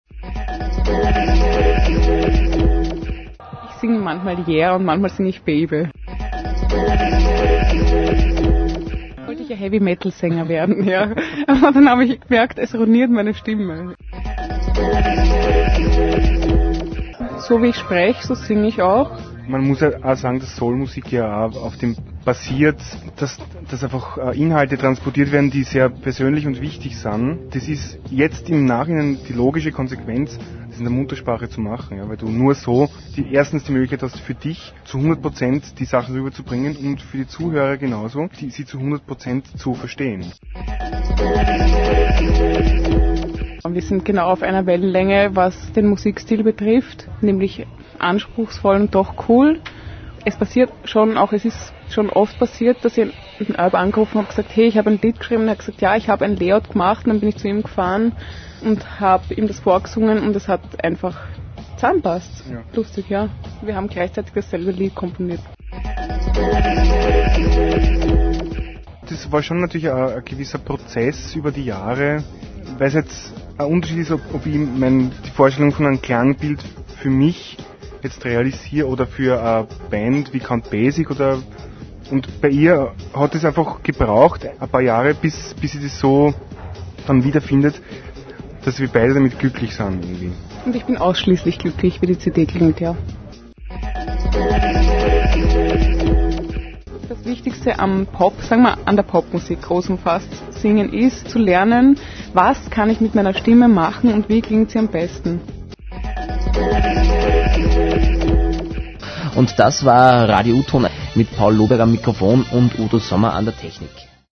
Interviewausschnitte aus Radio UTON